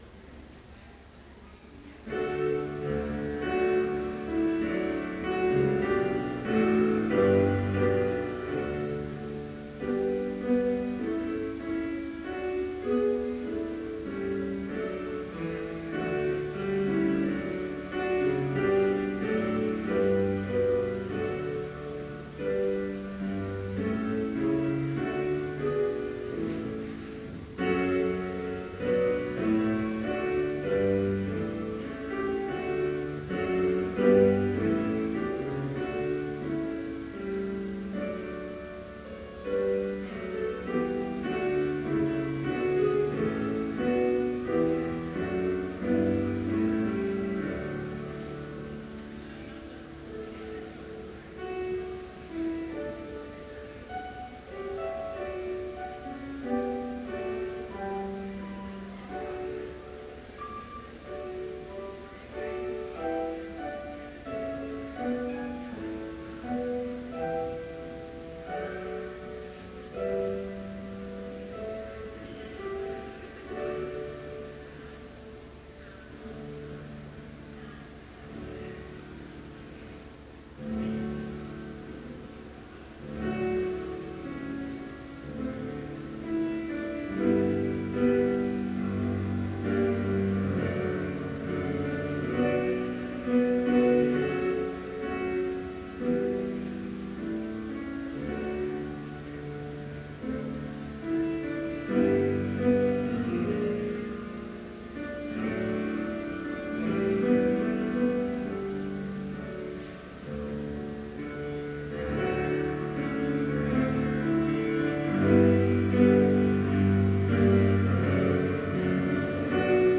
Complete service audio for Chapel - November 25, 2014